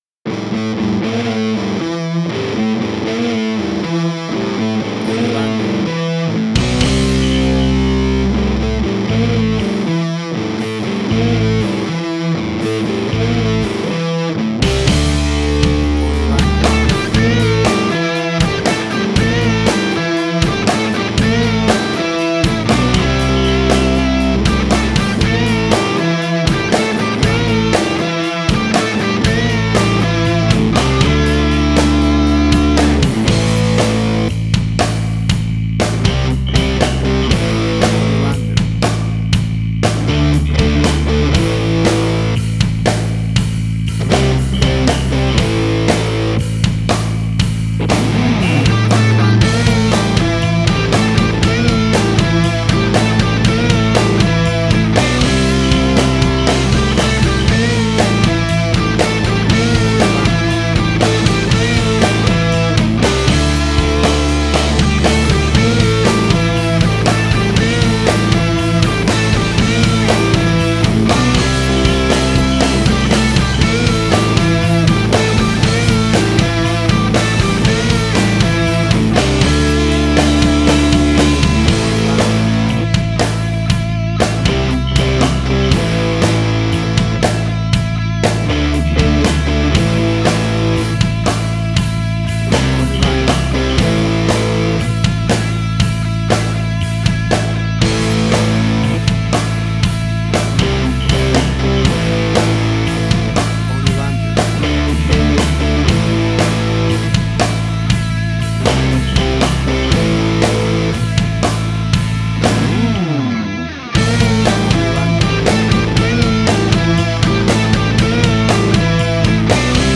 Hard Rock, Similar Black Sabbath, AC-DC, Heavy Metal.
Tempo (BPM): 120